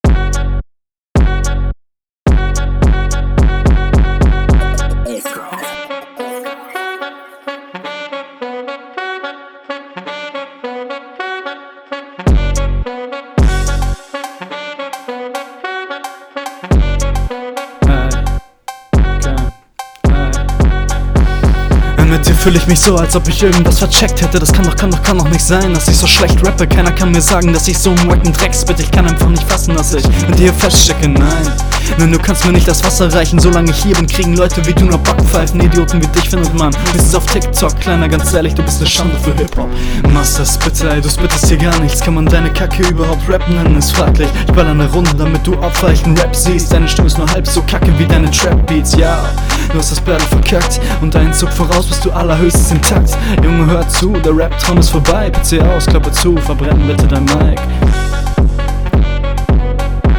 intro arschlang (aspang)